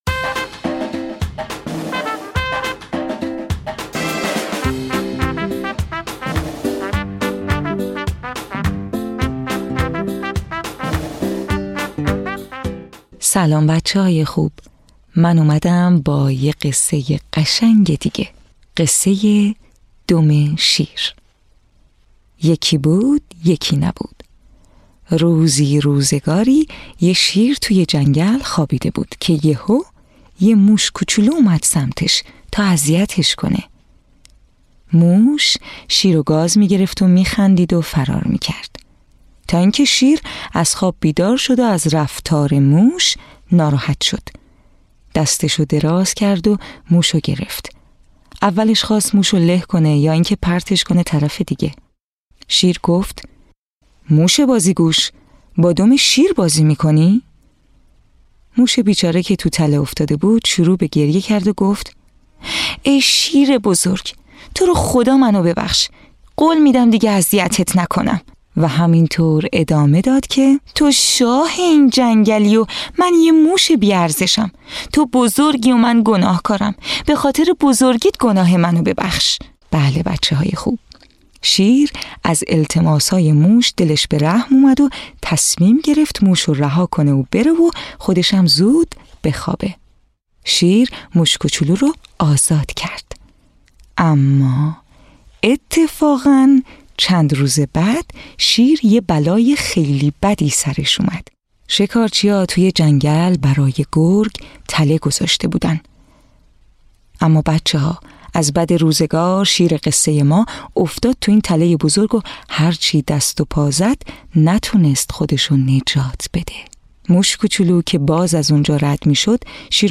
قصه های کودکانه صوتی- این داستان: دم شیر
تهیه شده در استودیو نت به نت